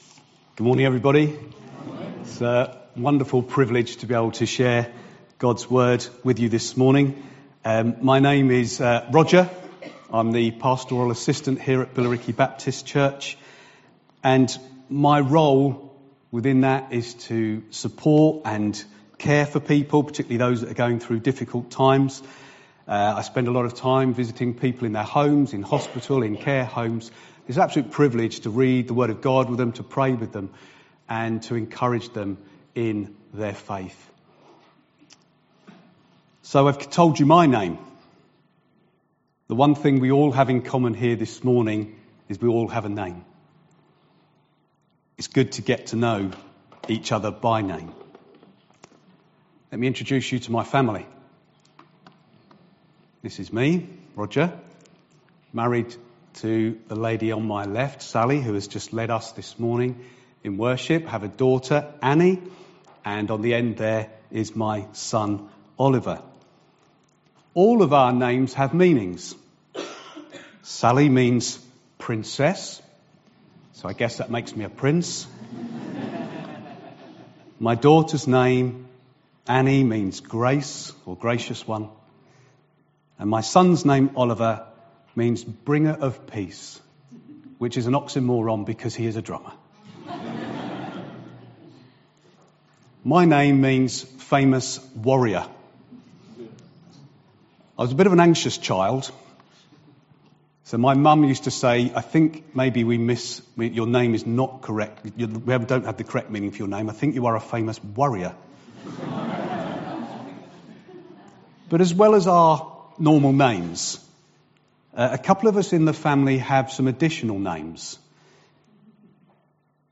Billericay Baptist Church - sermons Podcast - The Names of God - 1.